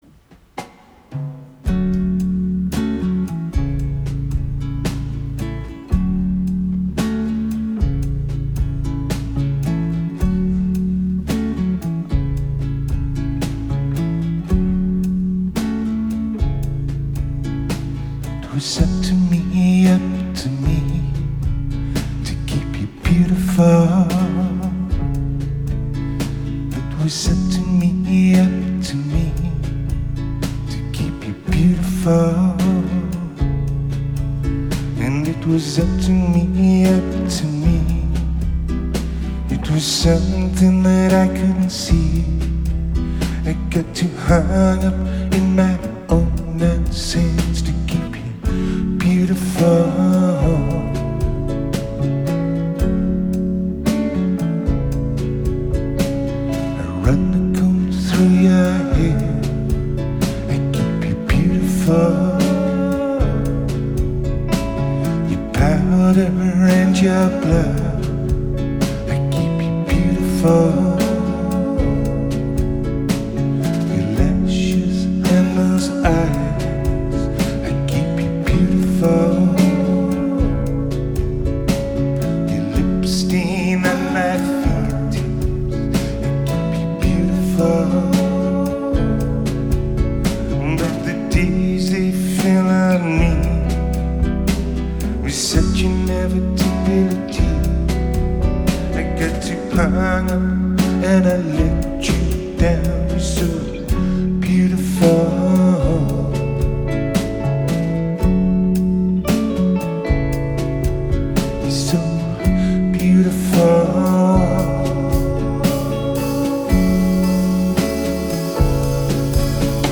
Genre: Alternative, Indie Rock, Chamber Pop